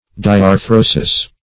diarthrosis - definition of diarthrosis - synonyms, pronunciation, spelling from Free Dictionary
Diarthrosis \Di`ar*thro"sis\, n. [NL., fr. Gr.